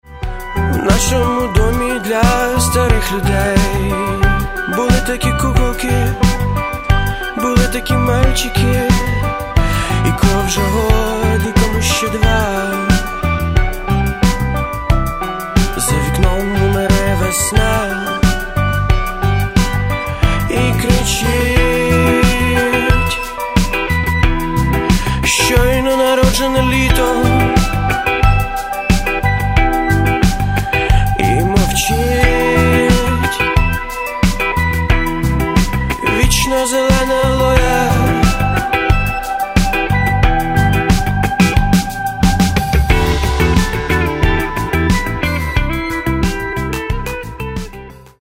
Каталог -> Рок и альтернатива -> Поп рок